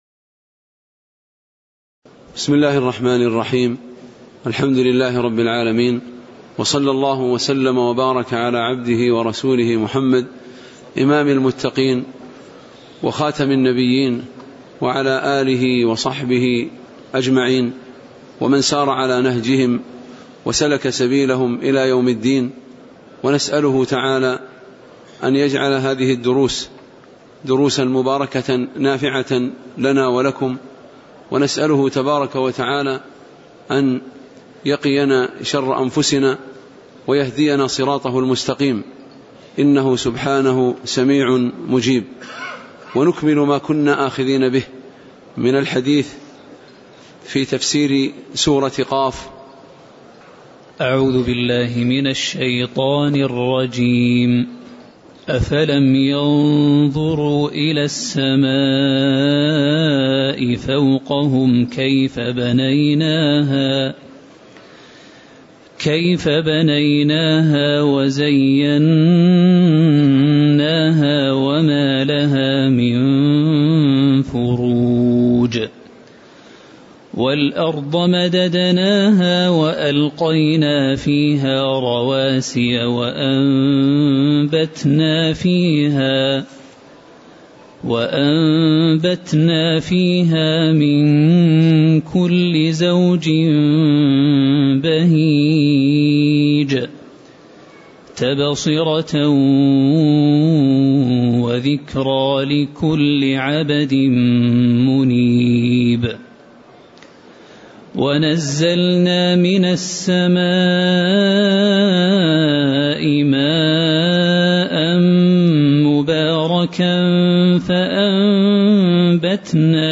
تاريخ النشر ٢٧ ربيع الثاني ١٤٣٩ هـ المكان: المسجد النبوي الشيخ